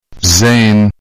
La prononciation des lettres arabes